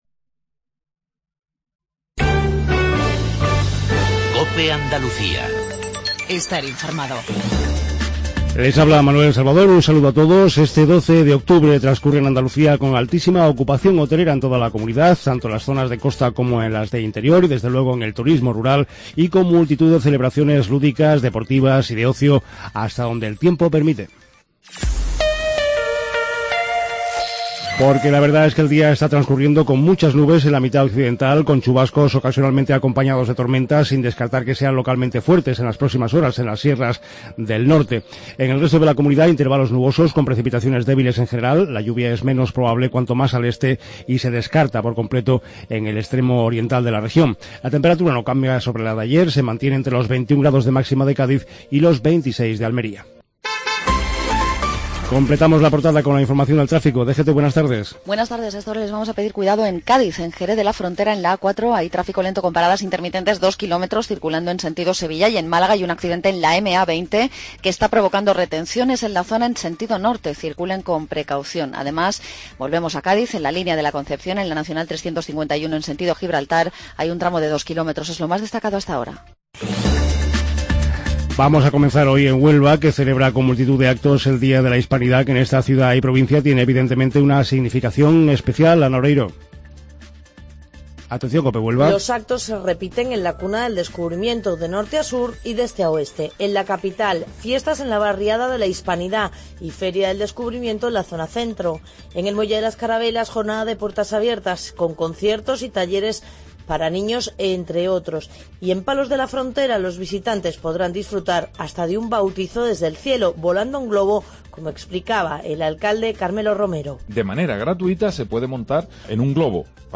INFORMATIVO REGIONAL/LOCAL MEDIODIA